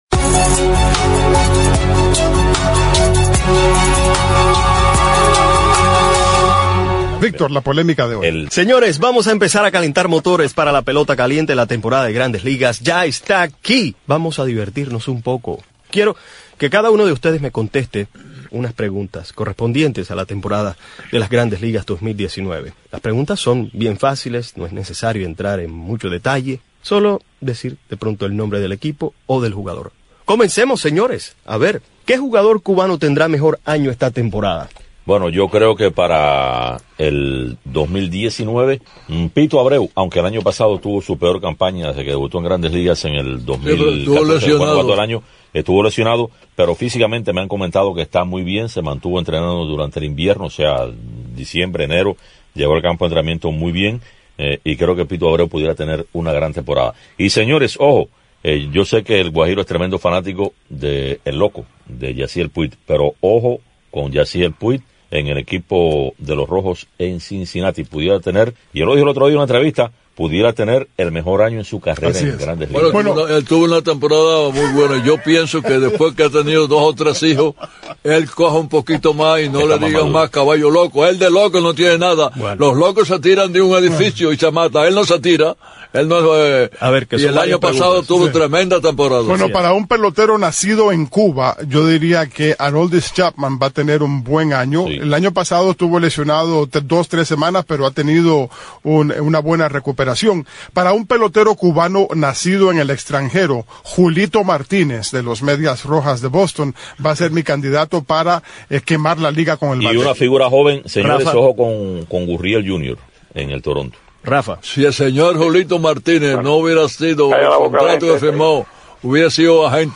Si quiere enterarse de lo que cada uno predijo para esta nueva temporada de Las Mayores, los invito a escuchar la polémica:
Recuerde que el segmento se hace con ánimos de debatir con respeto, si es que se llega a debatir.